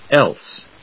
/éls(米国英語), els(英国英語)/